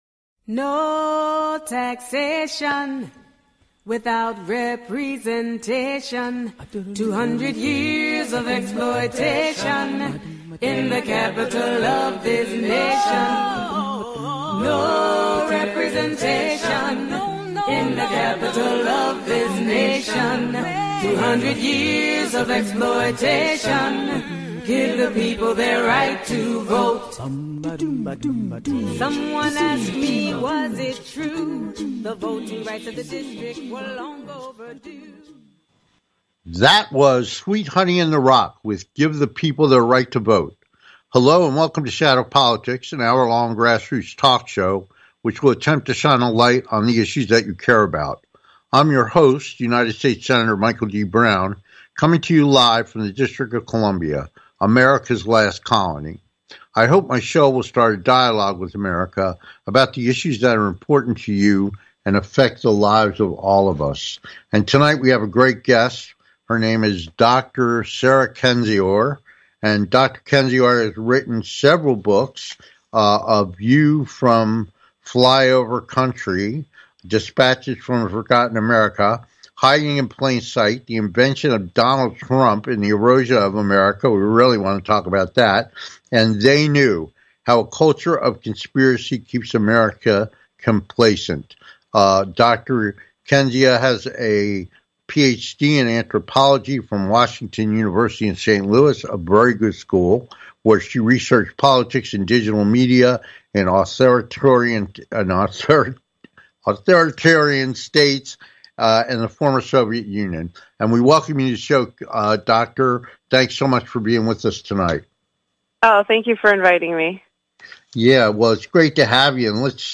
Talk Show Episode, Audio Podcast, Shadow Politics and Guest, Dr. Sarah Kendzior, Author and podcaster discussing how Donald Trump has hypnotized half of America. on , show guests , about Dr. Sarah Kendzior,Author,podcaster,Donald Trump,hypnotized,half of America, categorized as History,News,Politics & Government,Society and Culture
Will Vice President Harris undo his magic or will he continue to rise in popularity and have a real chance at reelection? Call in with your questions, because now is the time to ask.